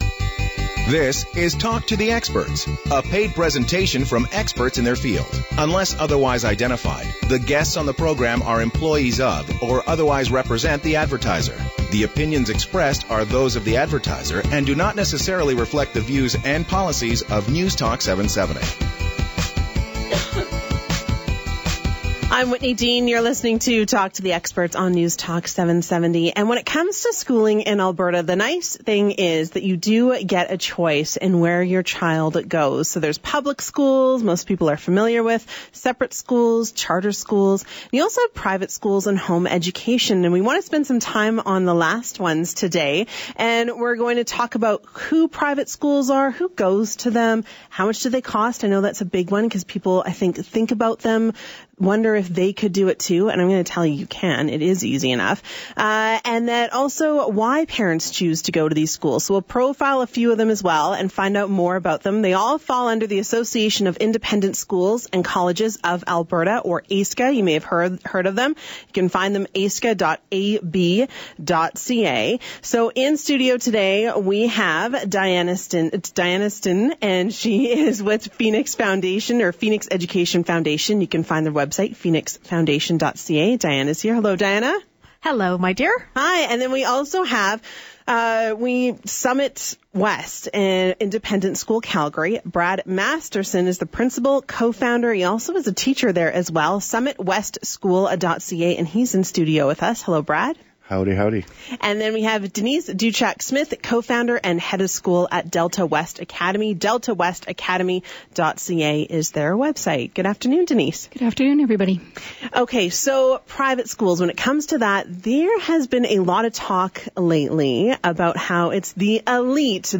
Each school was featured as well as a fantastic group discussion on the value of choice in education and why it is so important to give students and parents many options for learning.
talk-to-the-experts-independent-schools-march-12th.mp3